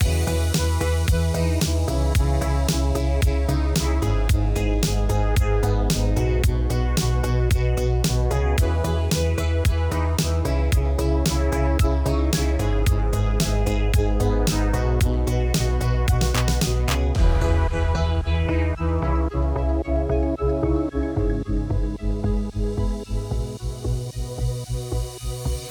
Synthwave_loop1.ogg